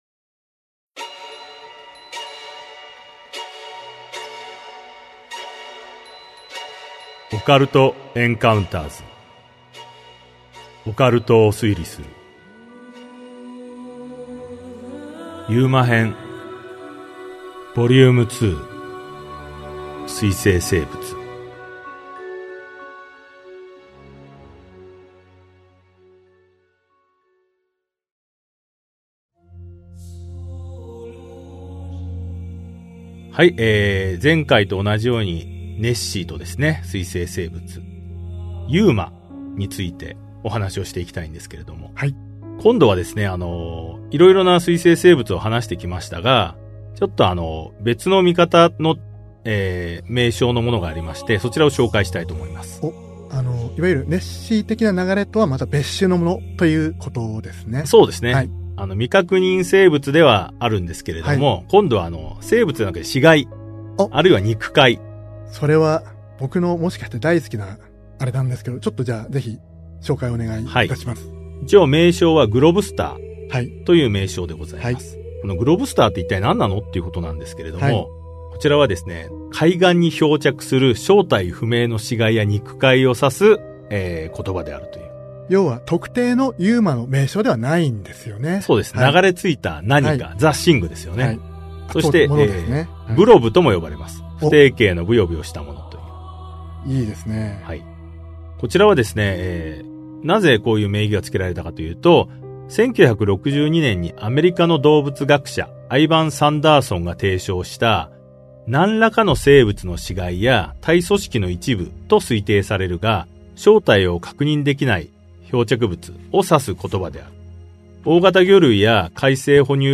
[オーディオブック] オカルト・エンカウンターズ オカルトを推理する Vol.08 UMA編2 世界の水棲生物